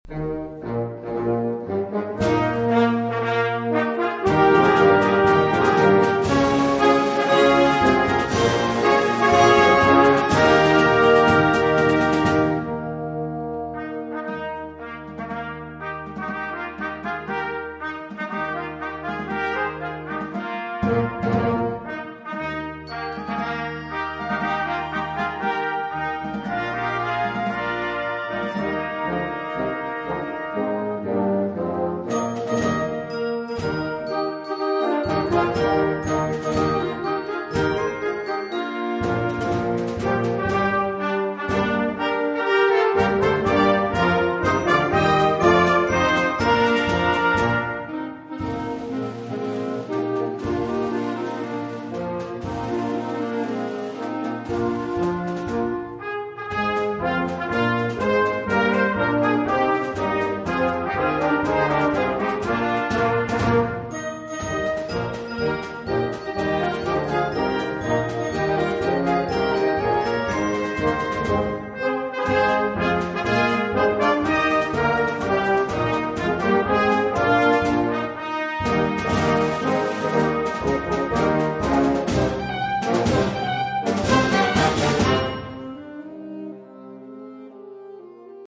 Blasorchester PDF